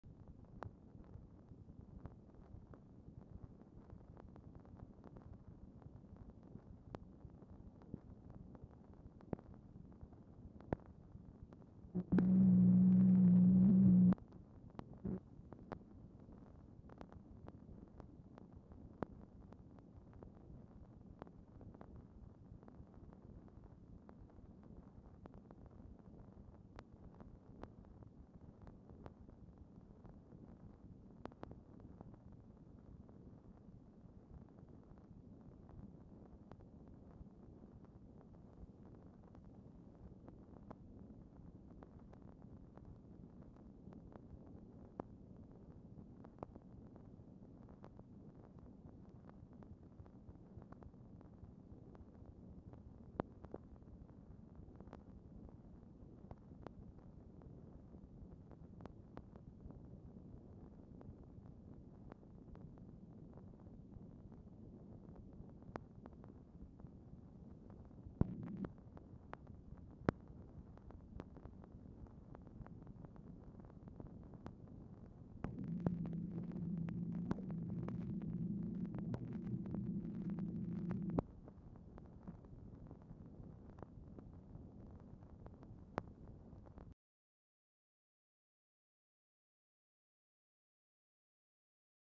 Telephone conversation # 8047, sound recording, MACHINE NOISE, 7/6/1965, time unknown | Discover LBJ
SEVERAL SHORT BANDS OF GROOVES SEPARATED BY UNRECORDED SPACES ON ORIGINAL DICTABELT
Dictation belt
White House Situation Room, Washington, DC